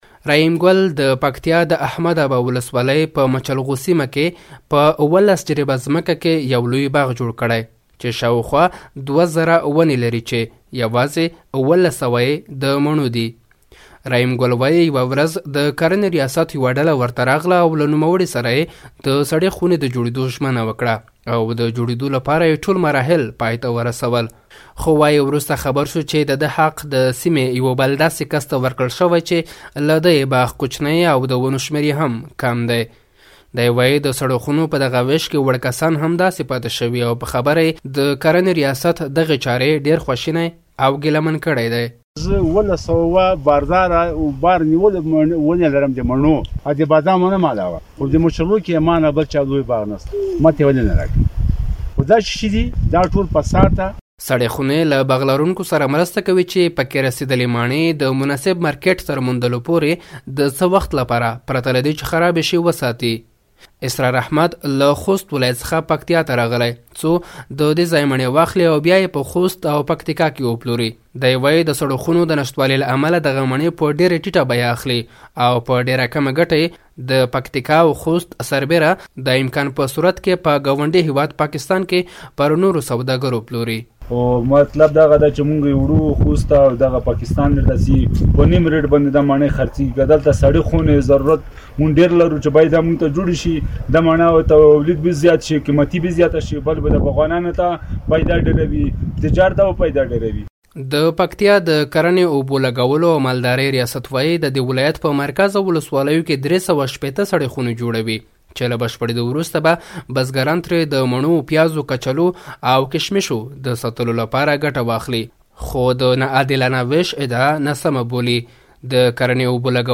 د پکتیا راپور